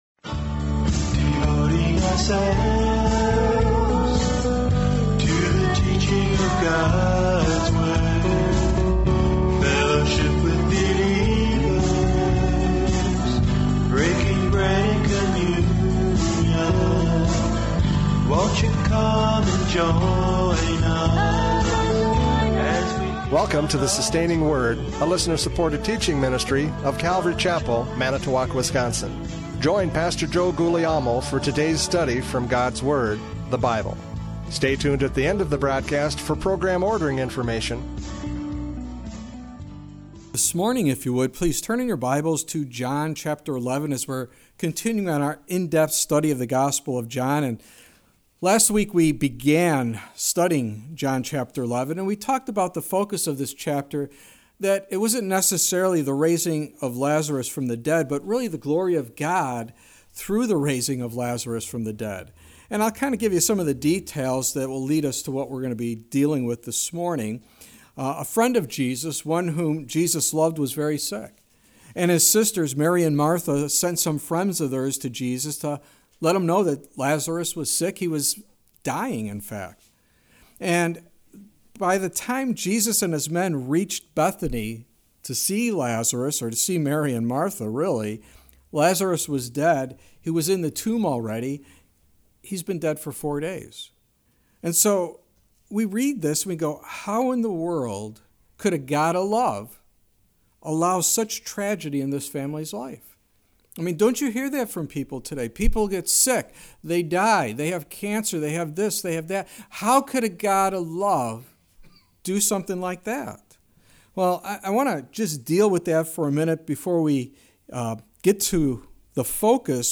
John 11:4-16 Service Type: Radio Programs « John 11:1-4 The Glory of God!